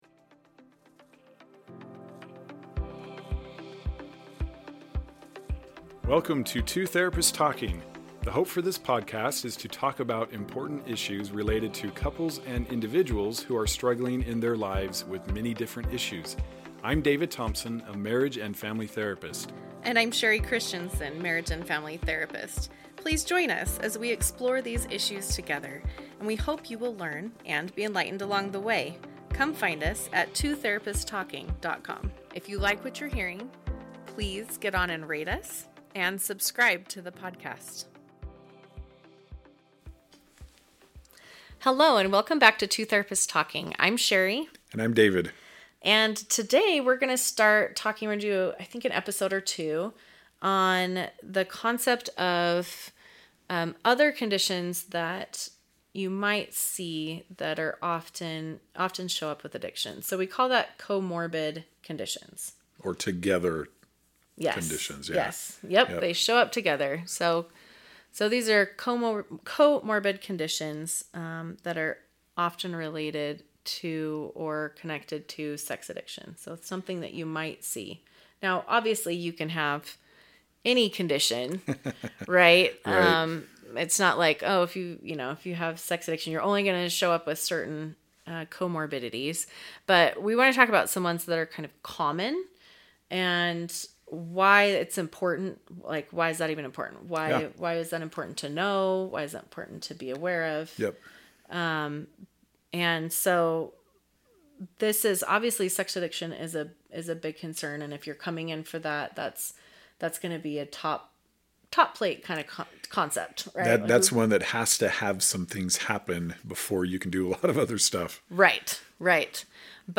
Two Therapists Talking